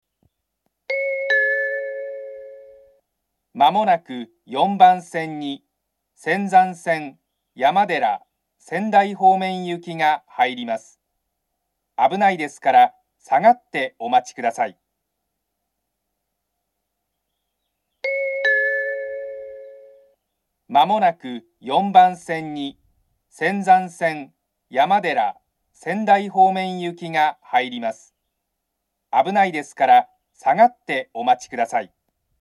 ４番線上り接近放送